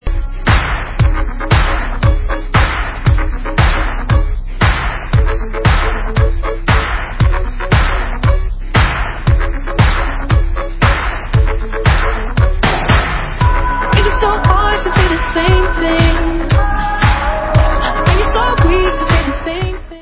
hrající fantastický R&B pop.